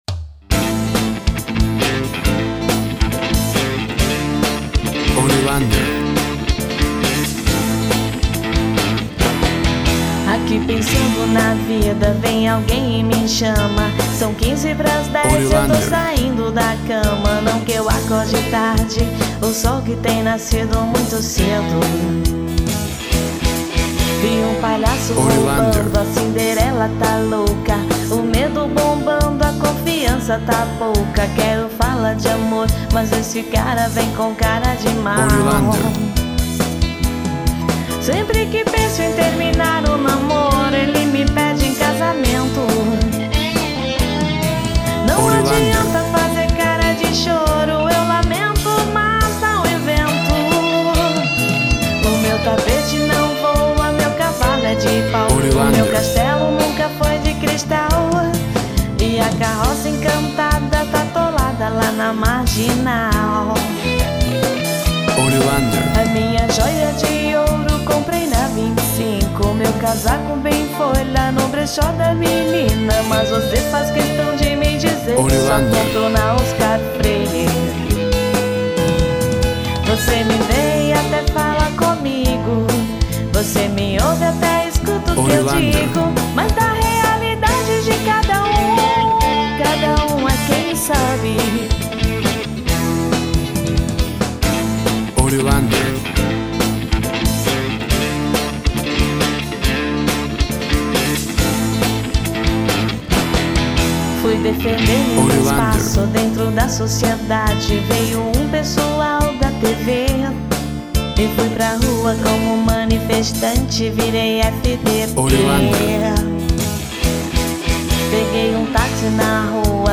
Pop Rock, MPB.
Tempo (BPM) 140